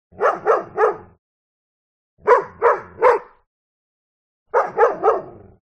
دانلود آهنگ سگ گرگی از افکت صوتی انسان و موجودات زنده
دانلود صدای سگ گرگی از ساعد نیوز با لینک مستقیم و کیفیت بالا
جلوه های صوتی